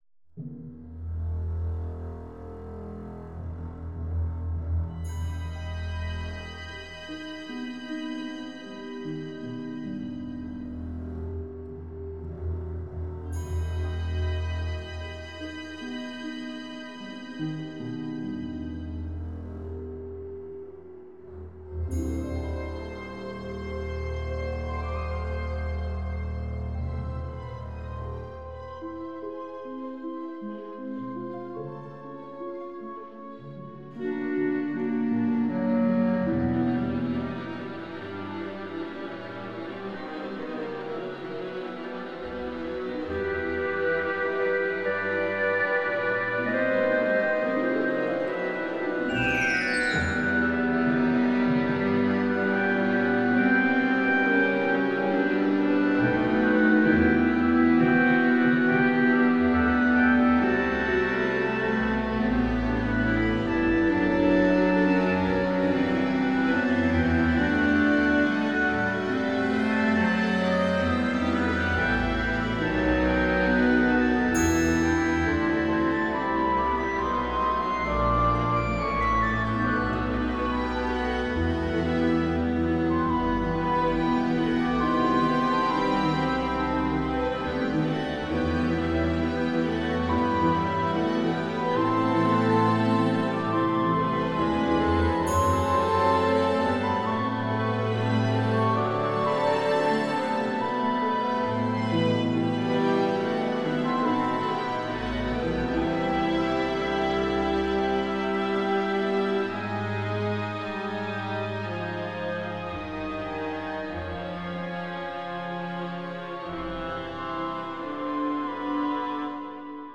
Perform your music with virtual instruments